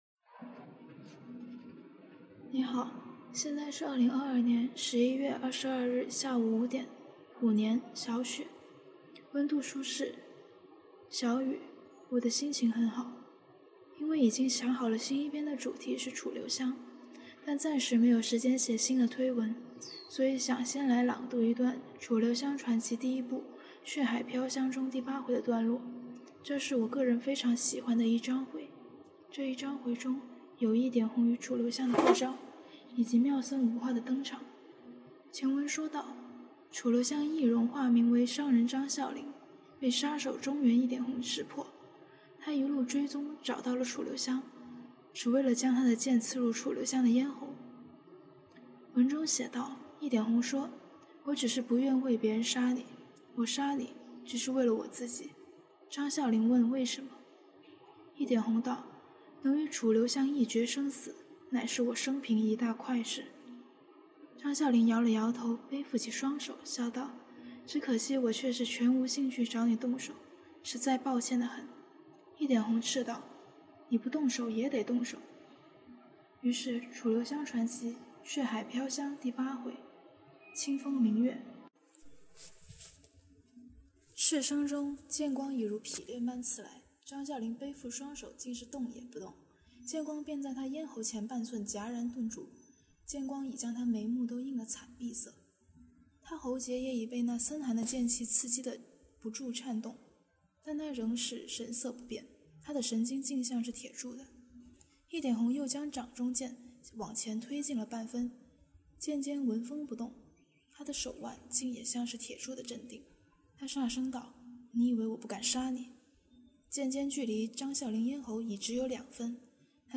朗读文本
嗨！突然上线给大家读一段楚传！有非常多口误，但大家都很熟了，错别字我能写得，错别话我怎么说不得？
我很喜欢这第八回，这段非常可爱好玩，也非常美：月下杀人手！白衣抚琴僧！是香香和红第一次贴贴，也是美人无花的初次登场。因为后面看这段太好玩太可爱了，所以有好多笑场和口胡，一边读一边想这不就是野蛮女友和你背地里蔫儿坏的初恋白月光嘛！